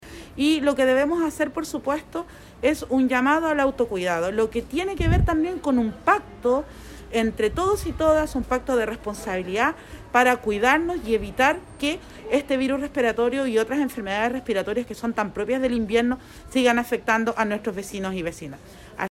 Palabras a las que se suman las de Nataly Carvajal Carvajal, Delegada Presidencial de Choapa, que hizo énfasis en un